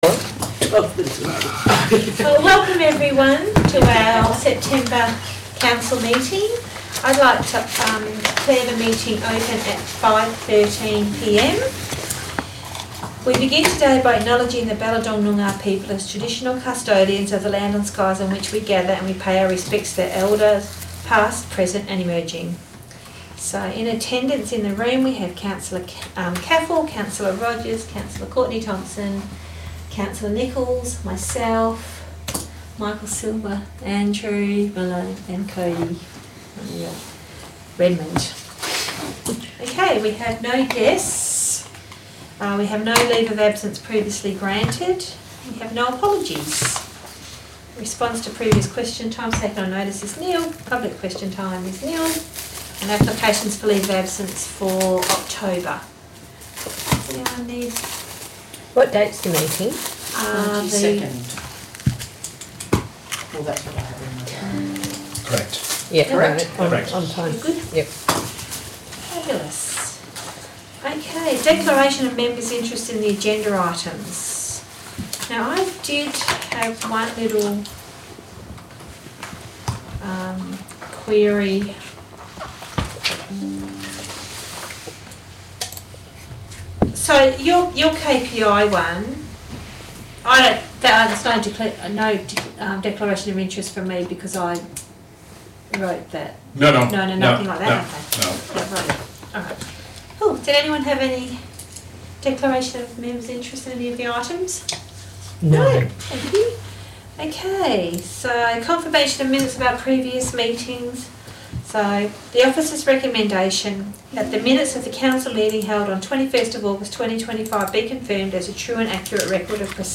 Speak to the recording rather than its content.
Location: Tammin Council Chambers